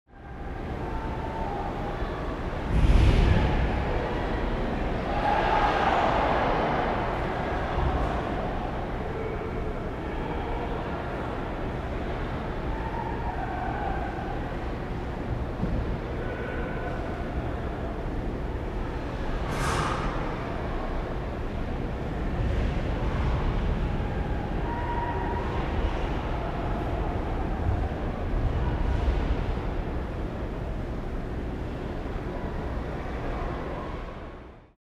Шум в раздевалке, из спортзала доносится гул болельщиков